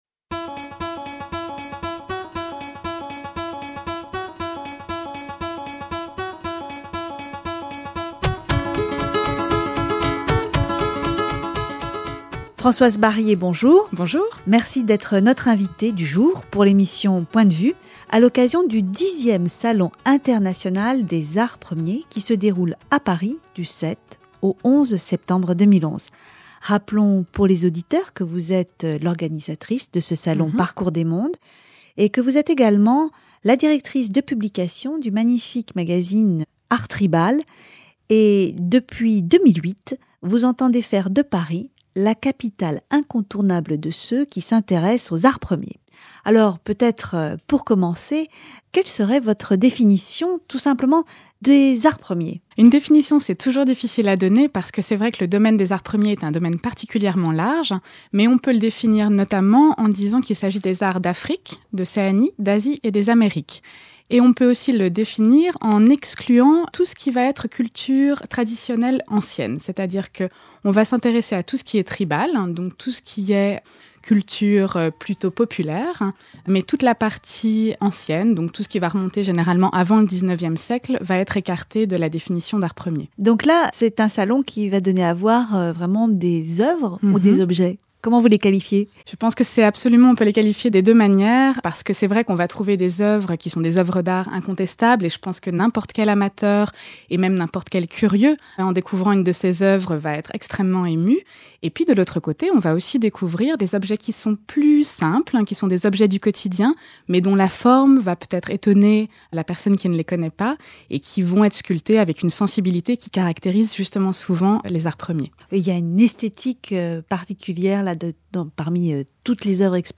Émission